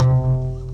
808s
Bass (9).wav